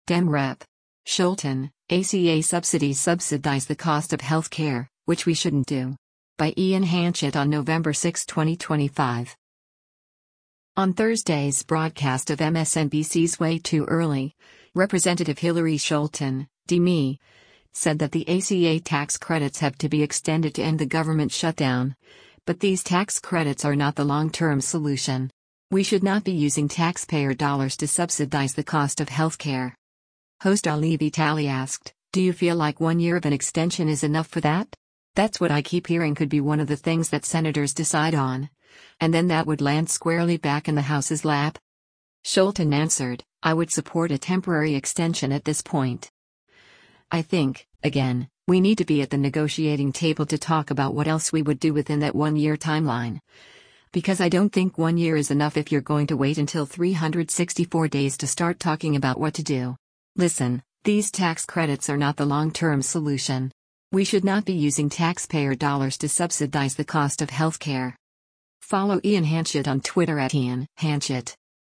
On Thursday’s broadcast of MSNBC’s “Way Too Early,” Rep. Hillary Scholten (D-MI) said that the ACA tax credits have to be extended to end the government shutdown, but “these tax credits are not the long-term solution. We should not be using taxpayer dollars to subsidize the cost of health care.”
Host Ali Vitali asked, “Do you feel like one year of an extension is enough for that? That’s what I keep hearing could be one of the things that senators decide on, and then that would land squarely back in the House’s lap?”